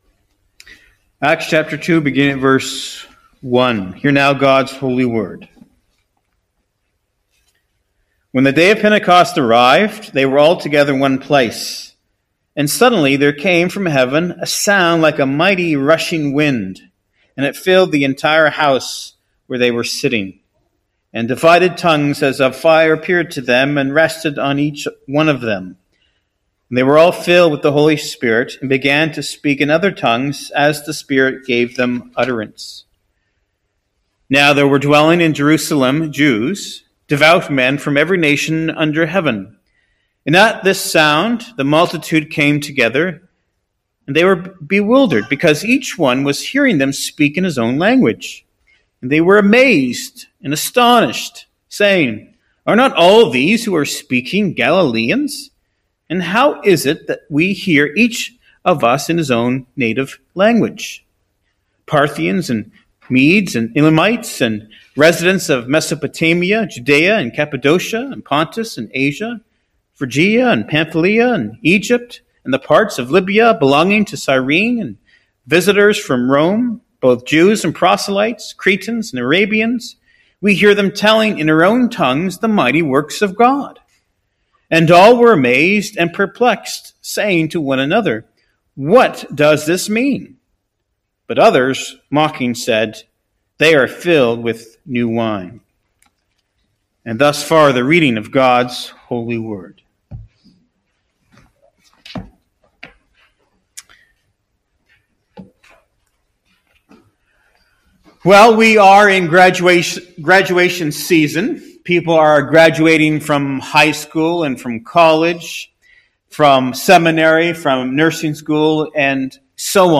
Below the mp3 is a list of important points from the sermon for your consideration: